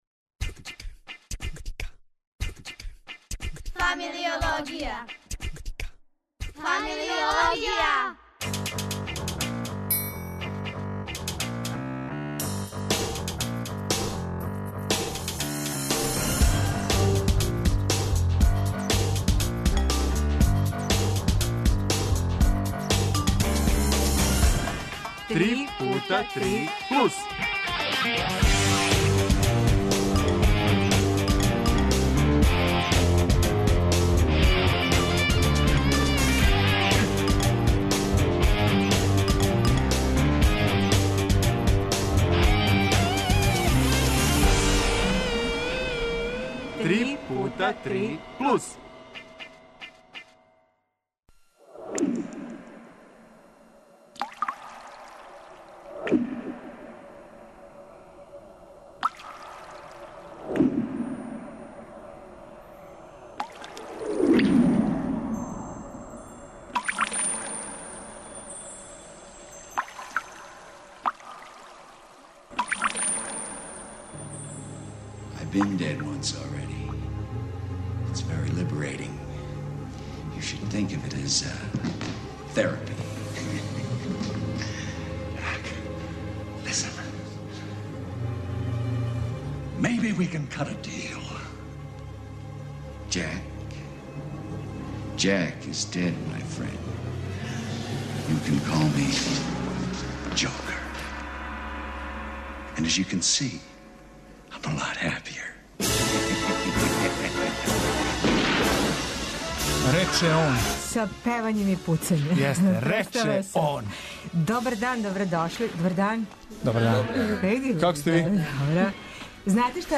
Гости су нам и овога пута млади из Мреже младих за инклузивно друштво.